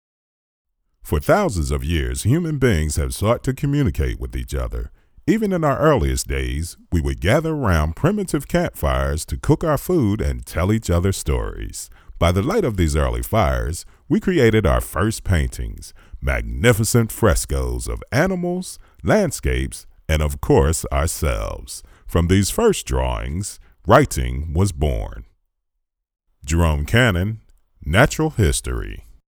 A Professional VoiceOver Service
mid-atlantic
Sprechprobe: Industrie (Muttersprache):